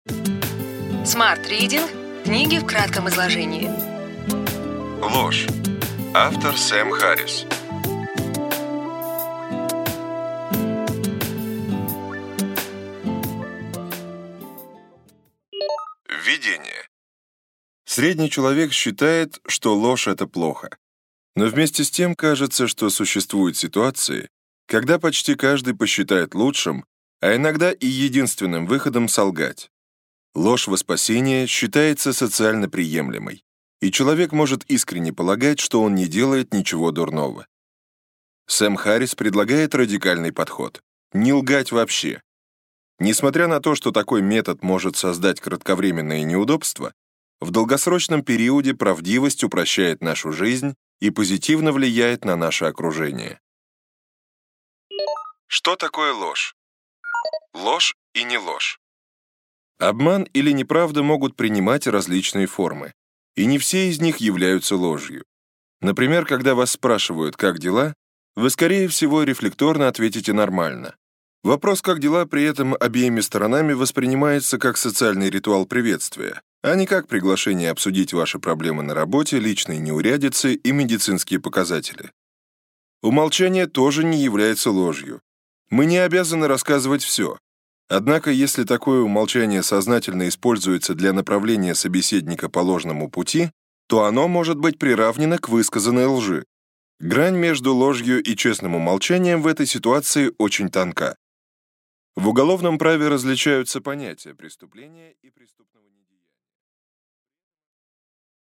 Прослушать и бесплатно скачать фрагмент аудиокниги